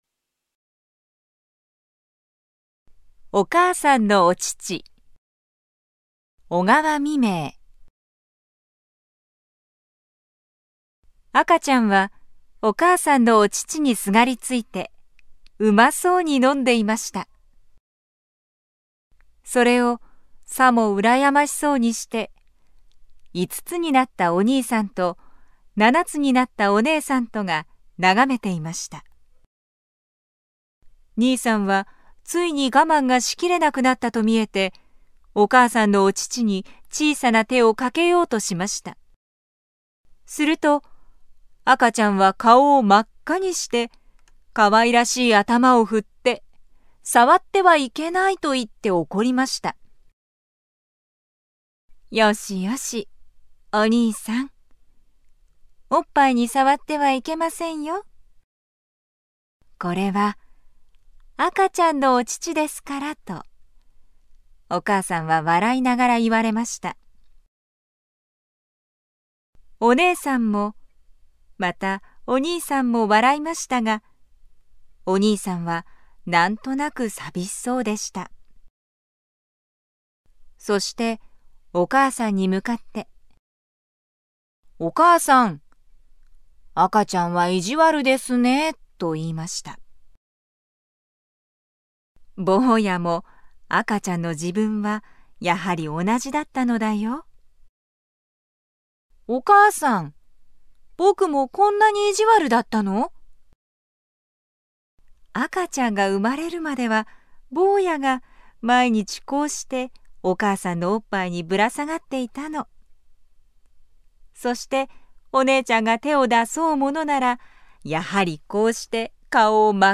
朗 読